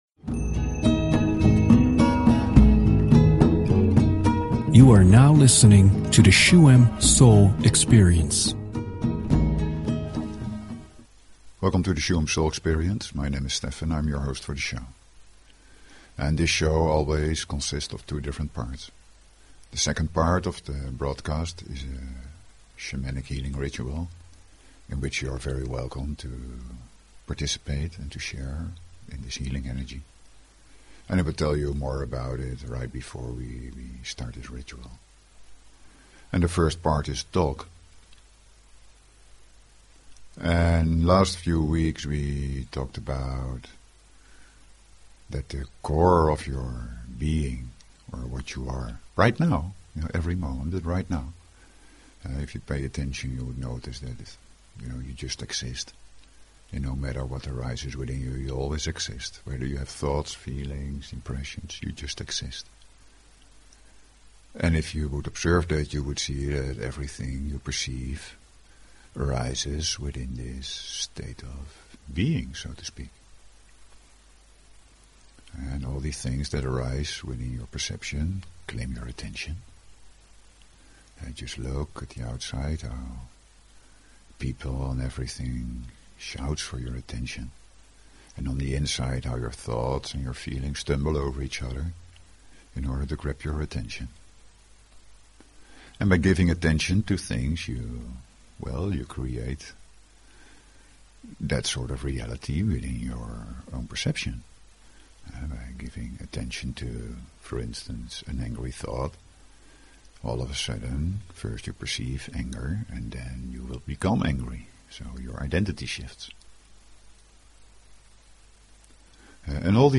Talk Show Episode, Audio Podcast, Shuem_Soul_Experience and Courtesy of BBS Radio on , show guests , about , categorized as
The second part of the show is a healingmeditation that helps to harmonize inner feelings. You can optimally share in this experience by listening to it through headphones, sit or lie down, relax and enjoy.